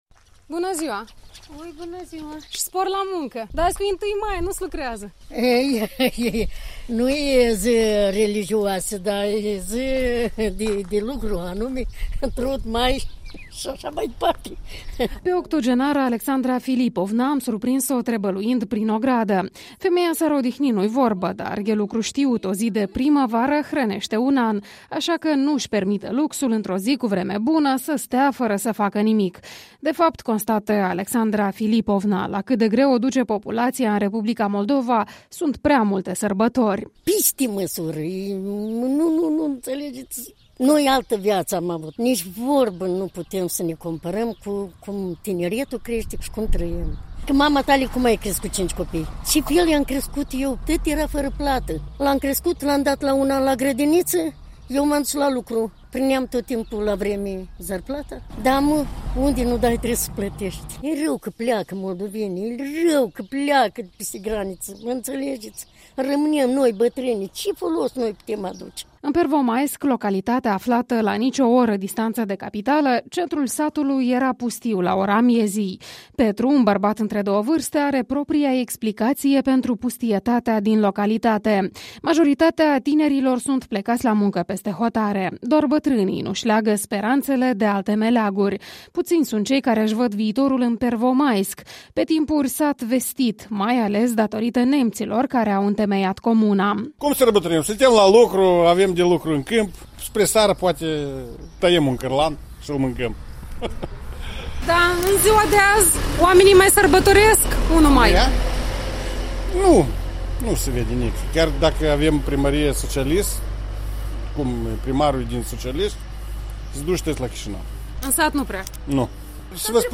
1 Mai în satul Pervomaisc, raionul Căuşeni